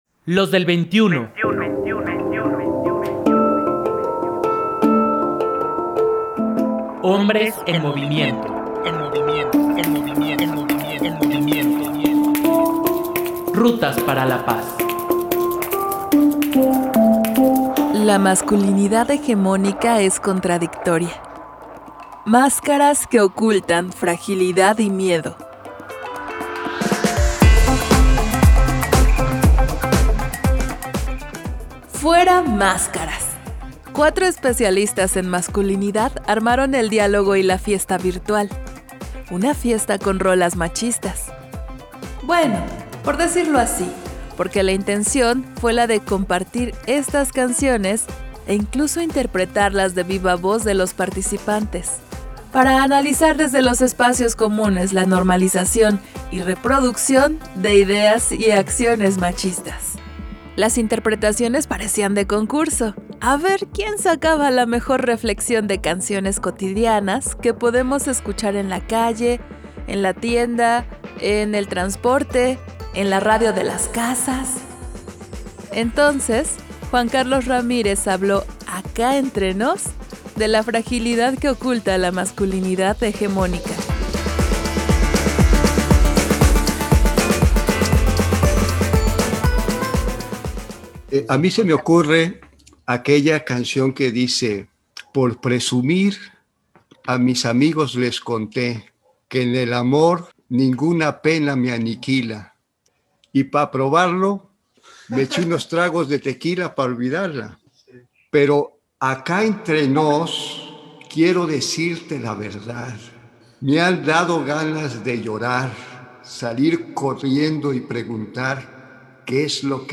¡Fuera máscaras! Cuatro especialistas en masculinidad armaron el diálogo y la fiesta virtual… una fiesta con rolas machistas; bueno, por decirlo así porque la intención fue la de compartir estas canciones -e incluso de interpretarlas de viva voz de los participantes- para analizar desde los espacios comunes la normalización y reproducción de ideas y acciones machistas.